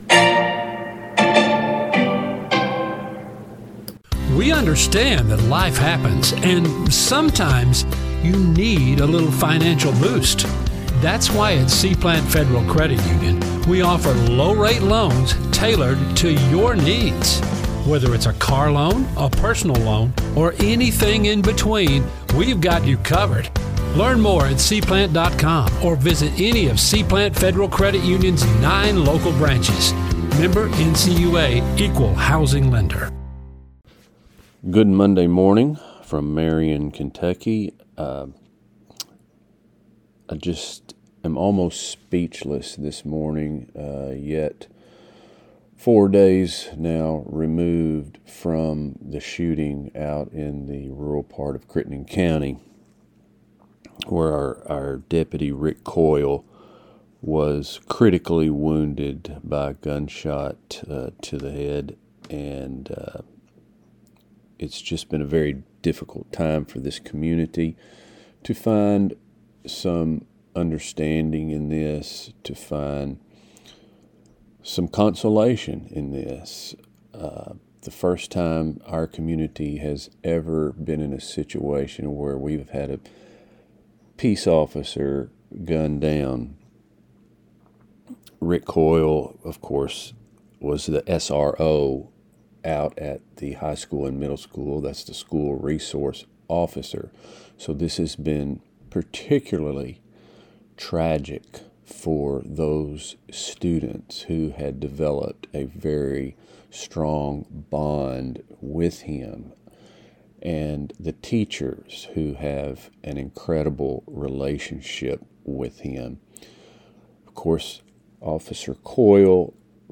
C-PLANT | Monday NEWScast LISTEN NOW News | Sports | More Today we visit with a local extension agent and talk about Child Abuse Prevention By Crittenden Press Online at April 06, 2026 Email This BlogThis!